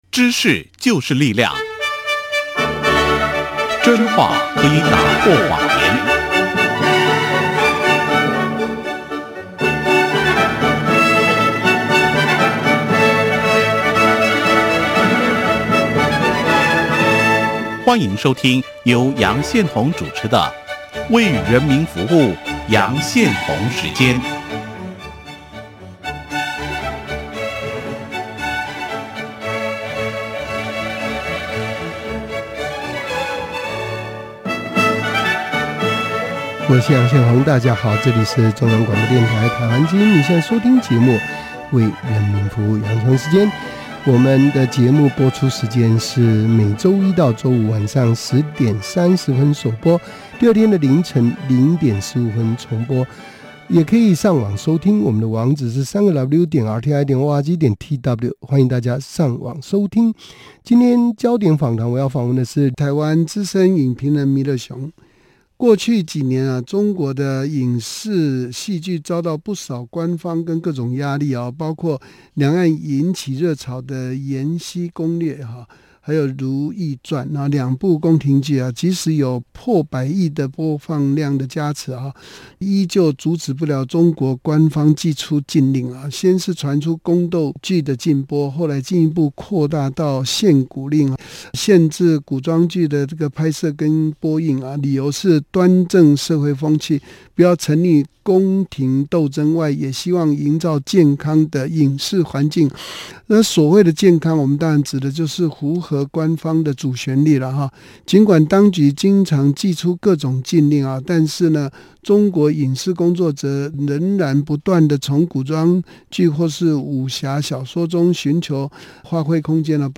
廣播影評